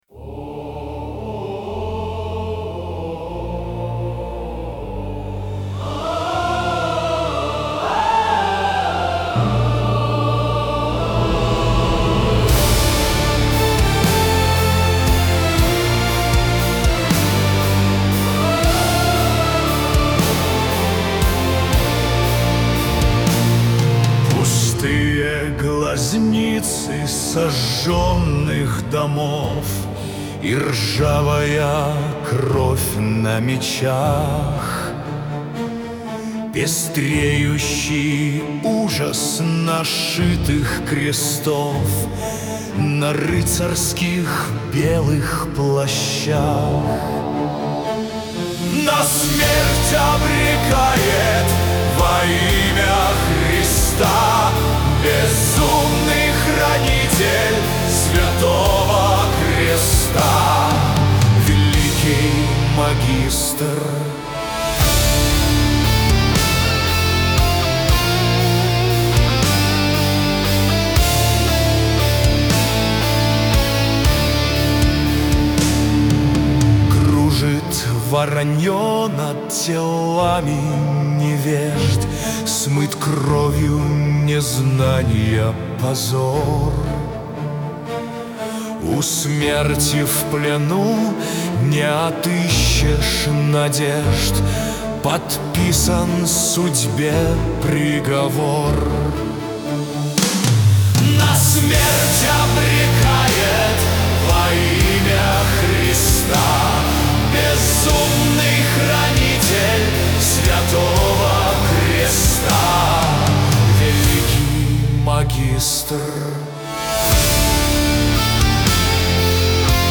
Оркестровые версии(1987,2024